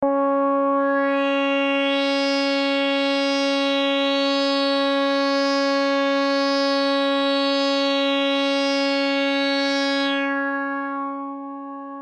标签： MIDI-速度-100 CSharp5 MIDI音符-73 ELEKTRON-模拟四 合成器 单票据 多重采样
声道立体声